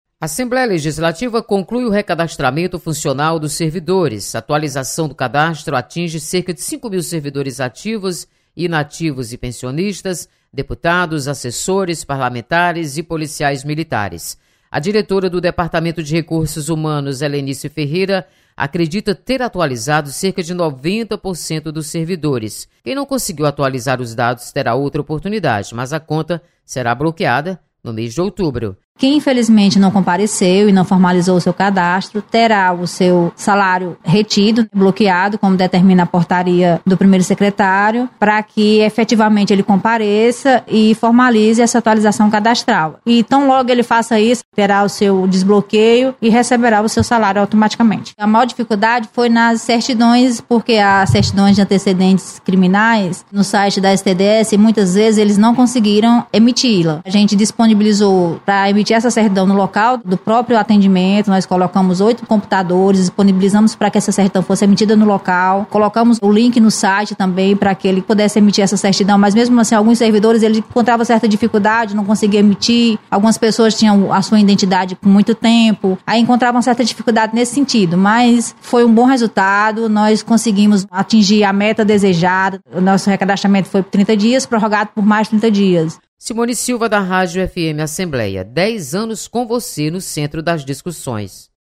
Assembleia conclui recadastramento de servidores. Repórter